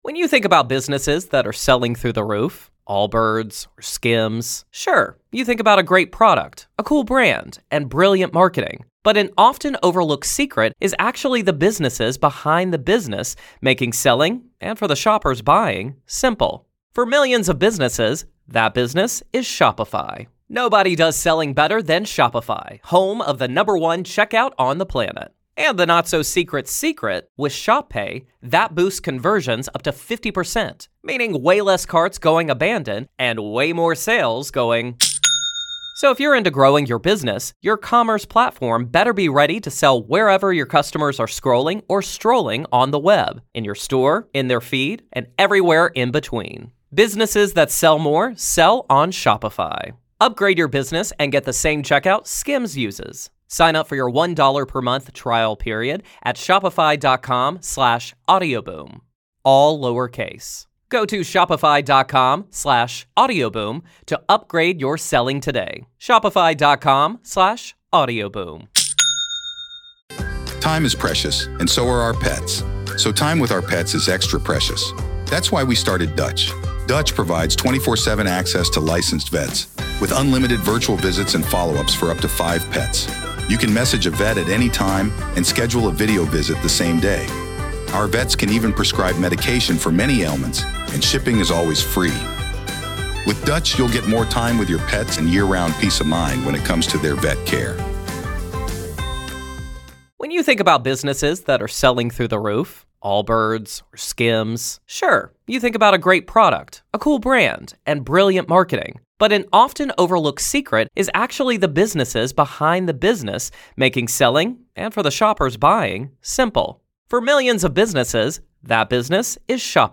With a mix of chills, sarcasm, and lived experience, they bring this unsettling story to life in the only way Real Ghost Stories Online can.